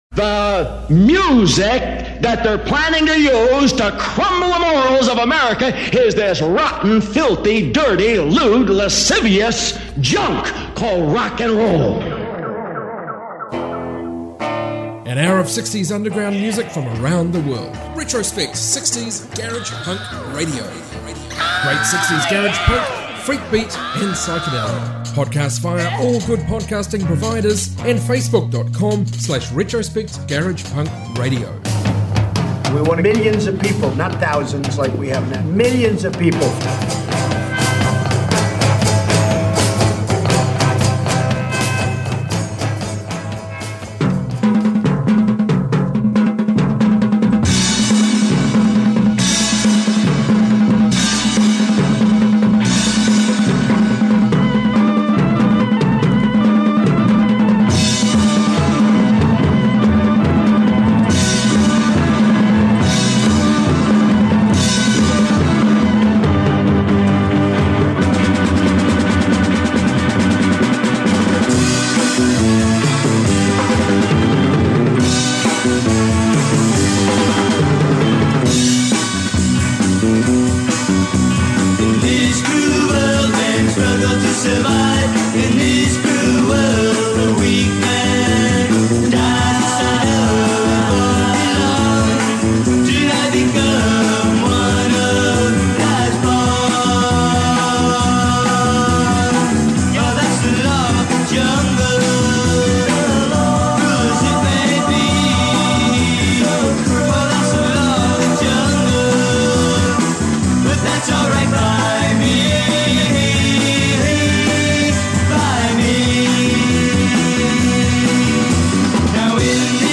60s global garage music